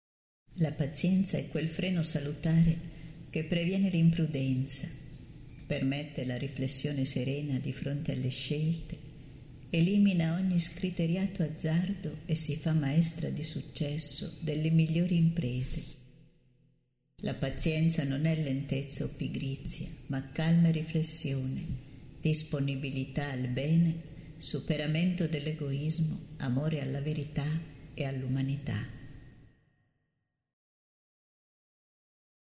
preghiera mp3 – La Pazienza canto mp3